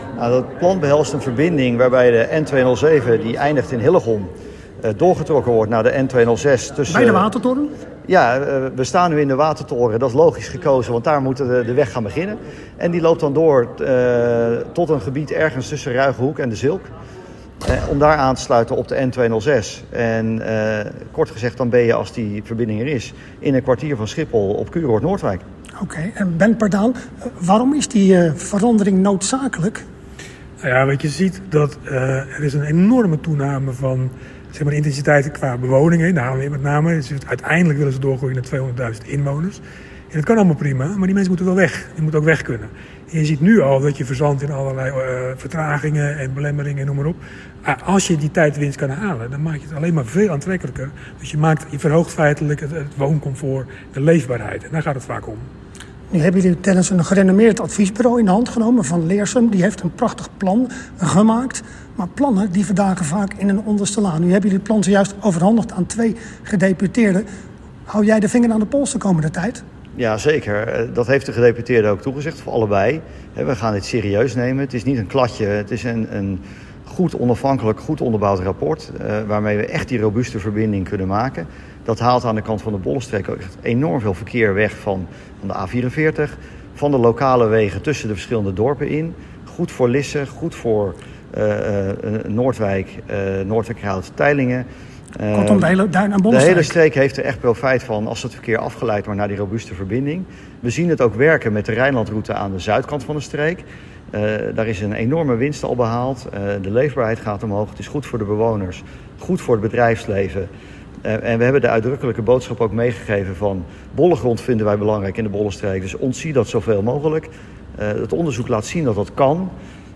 Hieronder de radio-interviews: